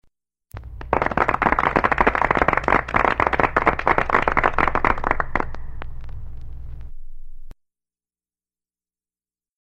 Applause
Form of original Audiocassette